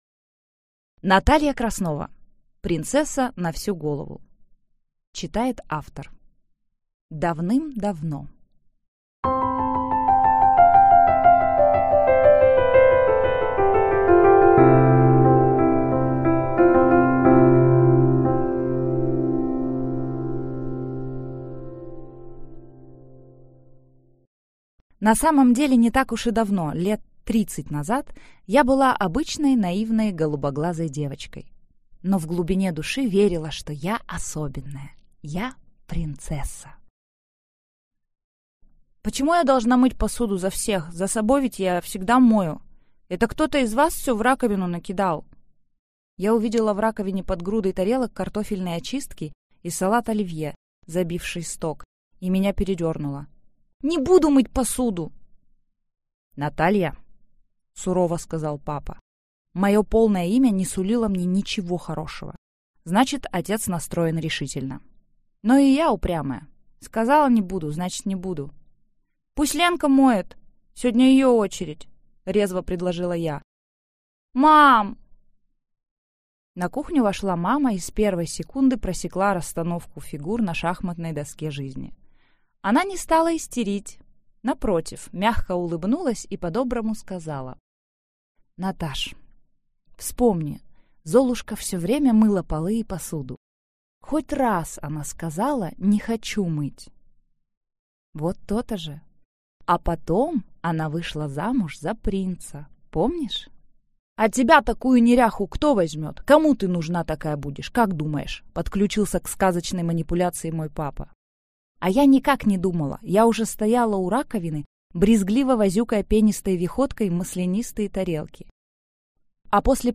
Аудиокнига Принцесса на всю голову. Почему мы влюбляемся в сказочных дураков | Библиотека аудиокниг